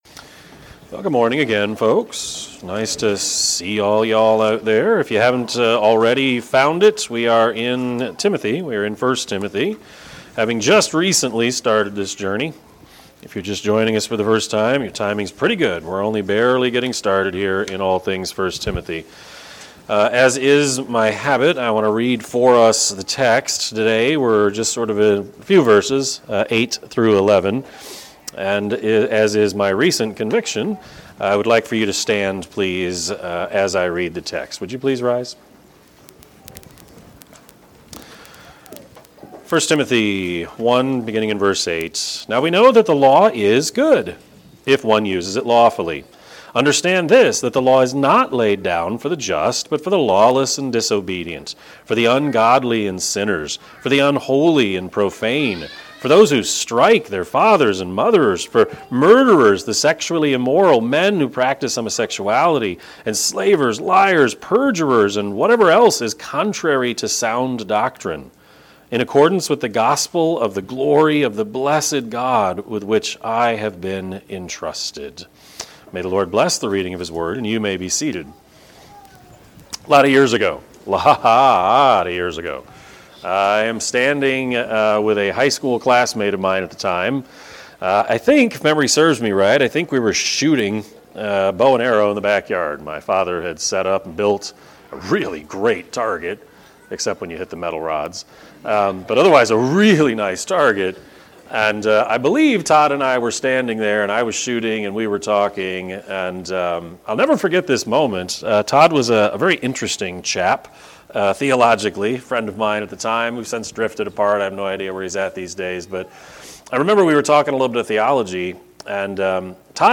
Sermon-4-30-23-Edit.mp3